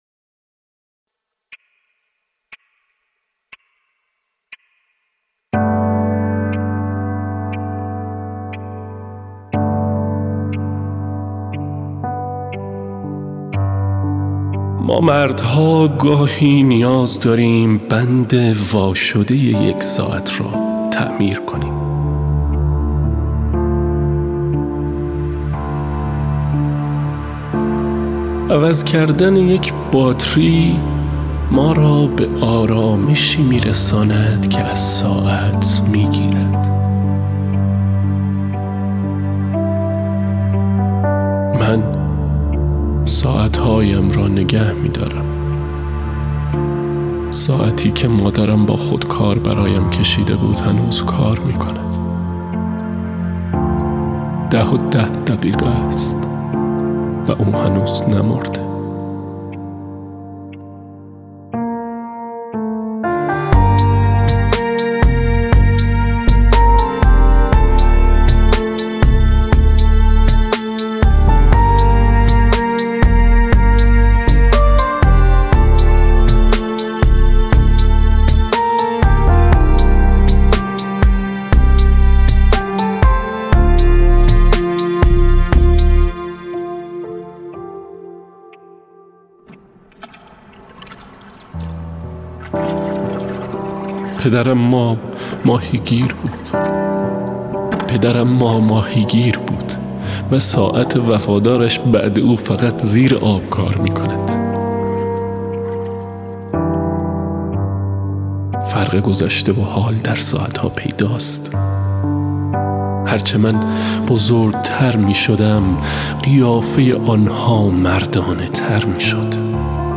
آهنگساز :   هایکا